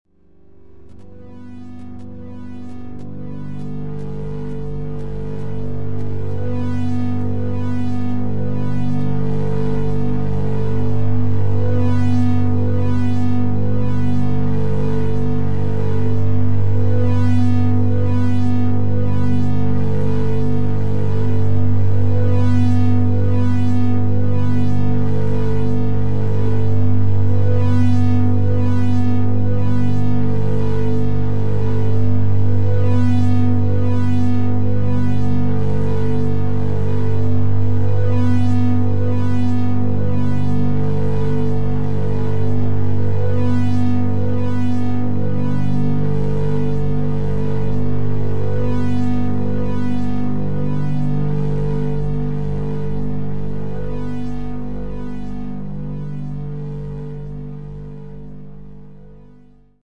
描述：用"precession"制作的60秒无人机，是我用reaktor制作的无人机发生器。
标签： 重aktor 电子 无人机
声道立体声